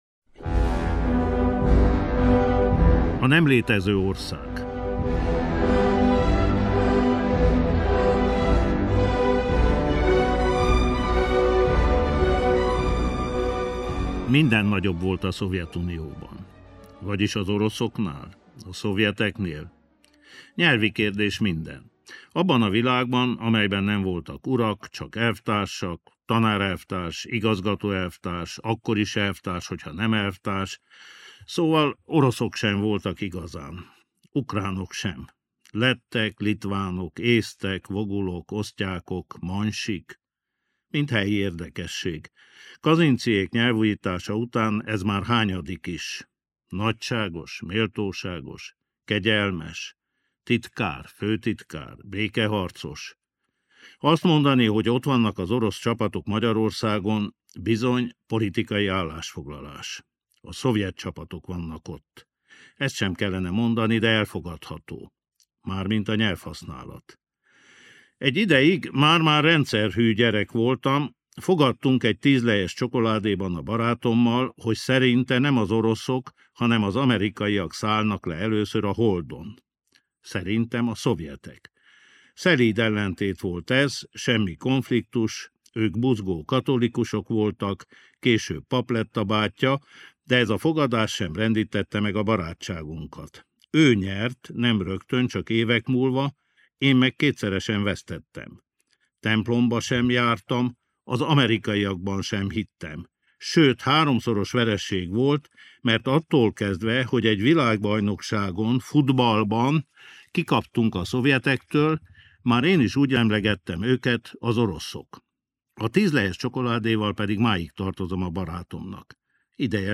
Markó Béla olvassa fel rövidprózáját.
Zenei illusztráció: Prokofjev orosz származású szovjet zeneszerző és zongoraművész Romeó és Júlia balettzenéjének, A kések tánca című tételéből származik.